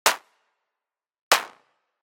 This is one of the claps from my soundpack, I was going for a old sample style clap - linndrum dmx etc